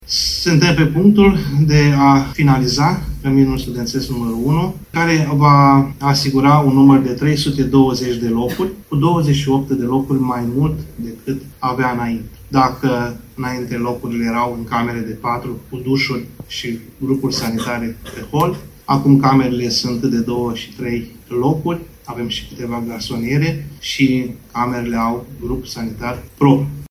VALENTIN POPA, rectorul Universității Ștefan cel Mare Suceava, a declarat astăzi, în ședința solemnă a Zilei USV, că instituția are posibilitatea să-și dezvolte propriile proiecte de investiții.